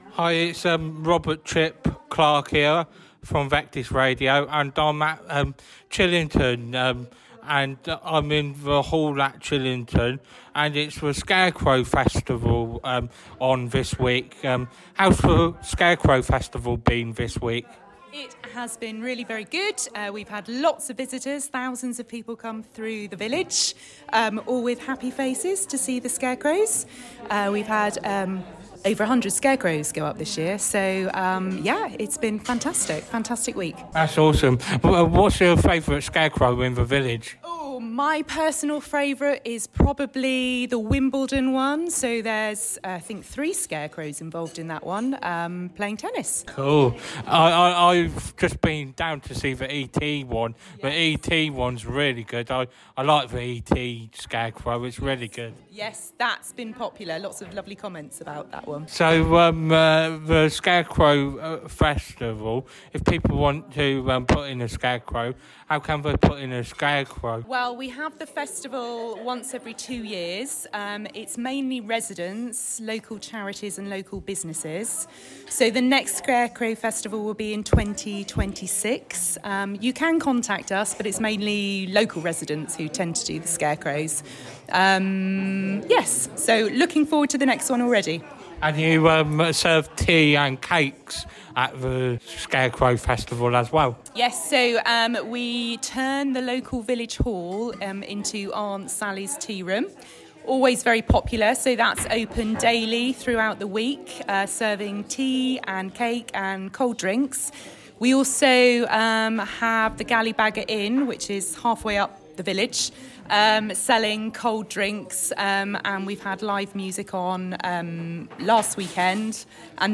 Scarecrow festival Interview 2024